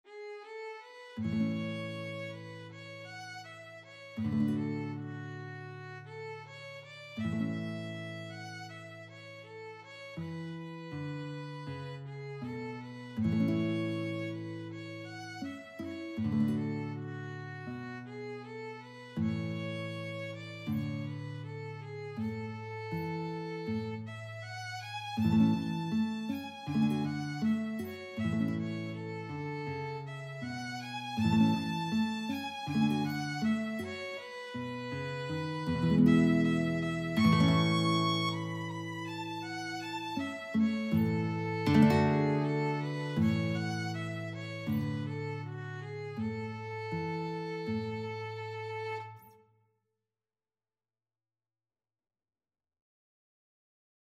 Free Sheet music for Violin-Guitar Duet
A major (Sounding Pitch) (View more A major Music for Violin-Guitar Duet )
4/4 (View more 4/4 Music)
Espressivo Andante
Traditional (View more Traditional Violin-Guitar Duet Music)
danny_boy_VLNGUIT.mp3